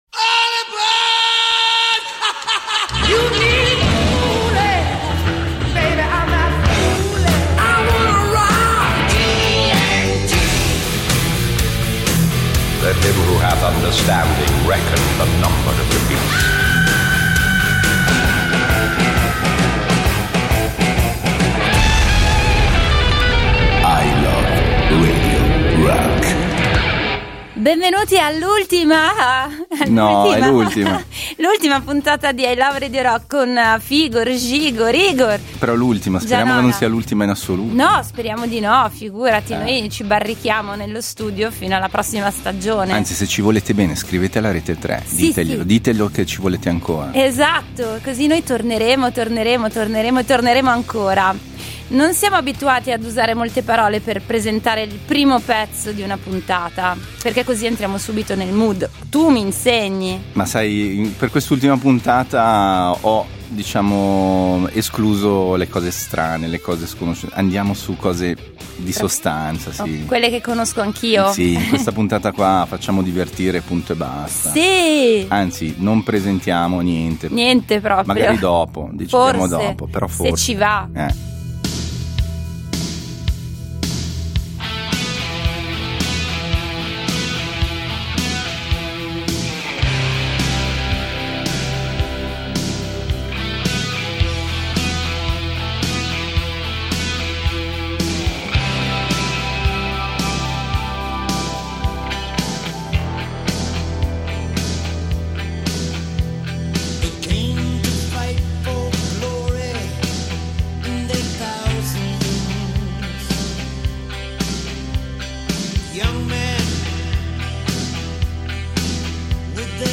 I love radio rock Ronnie Romero (Corleoni) 27.08.2019 59 min Contenuto audio Disponibile su Scarica Scopri la serie I love Radio Rock Il miglior programma di rock duro della Svizzera italiana.
il programma dedicato alle sonorità hard rock e heavy metal che hanno fatto la storia. https